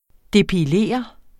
Udtale [ depiˈleˀʌ ]